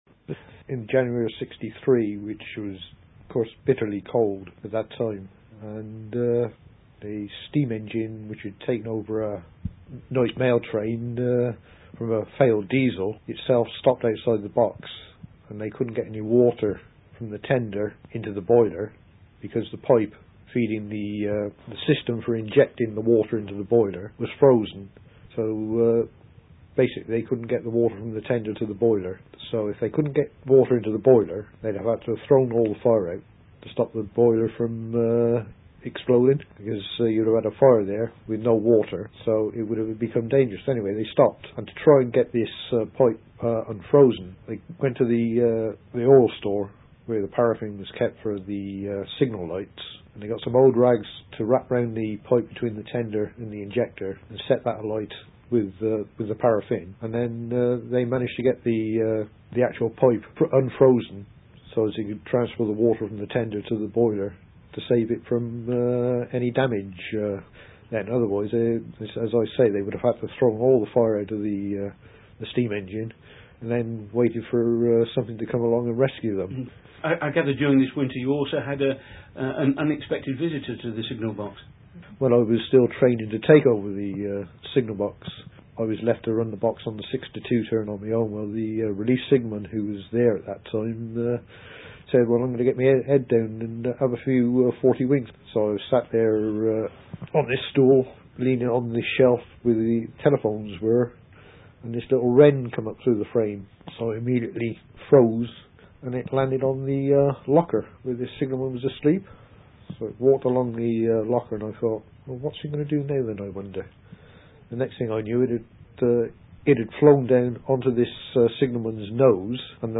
Second part of a recorded interview